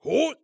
ZS蓄力3.wav
ZS蓄力3.wav 0:00.00 0:00.45 ZS蓄力3.wav WAV · 38 KB · 單聲道 (1ch) 下载文件 本站所有音效均采用 CC0 授权 ，可免费用于商业与个人项目，无需署名。
人声采集素材/男3战士型/ZS蓄力3.wav